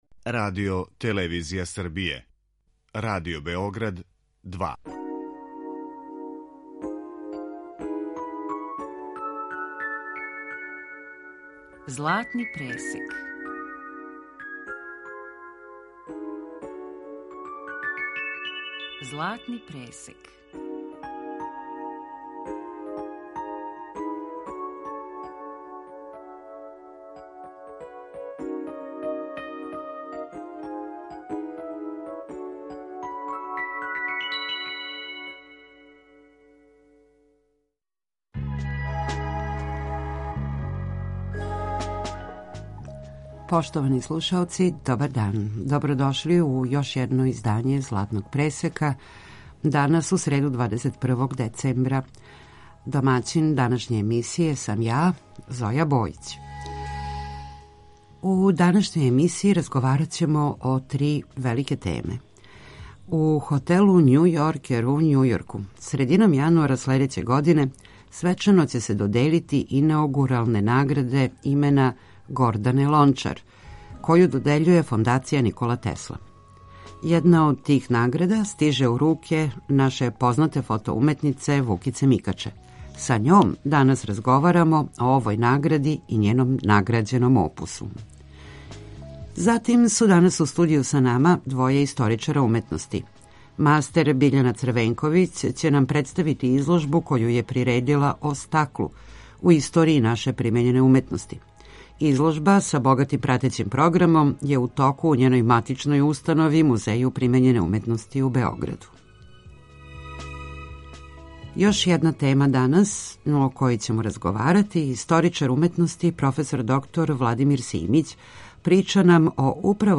Затим су данас у студију са нама двоје историчара уметности.